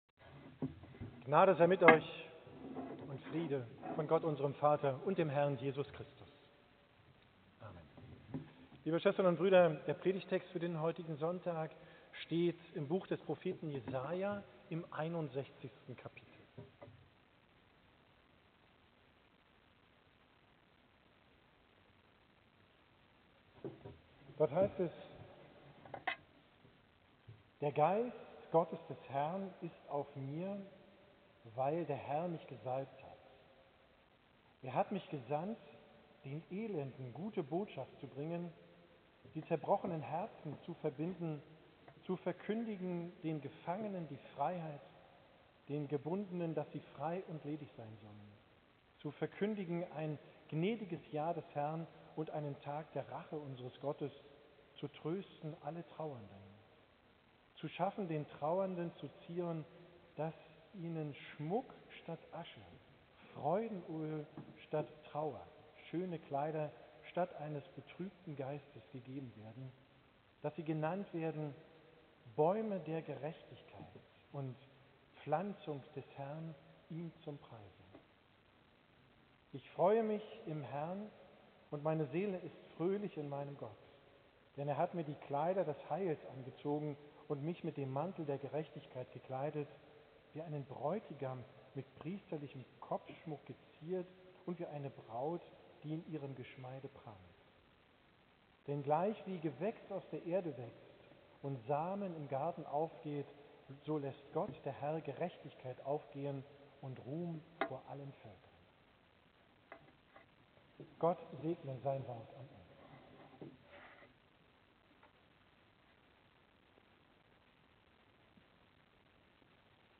Predigt zum 2.